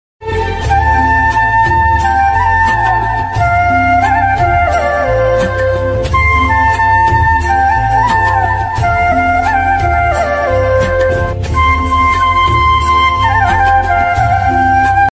Instrumental Ringtone Download.